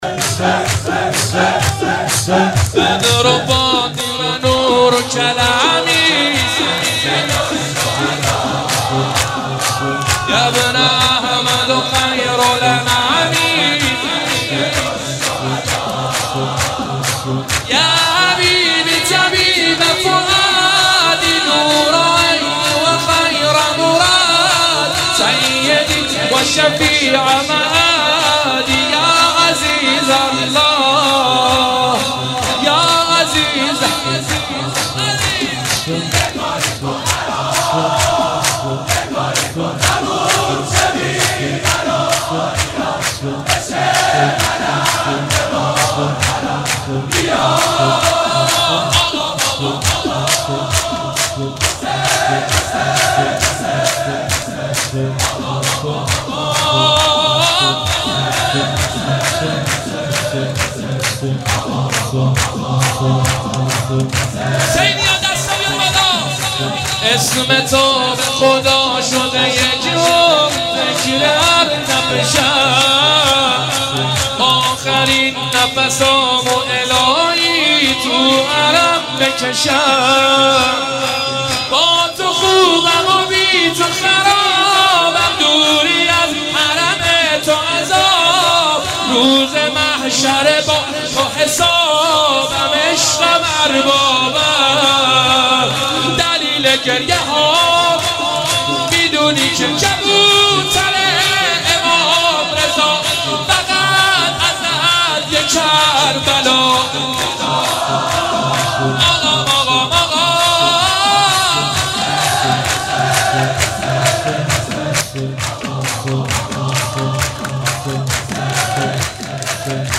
جشن نیمه شعبان/هیت روضه العباس(ع)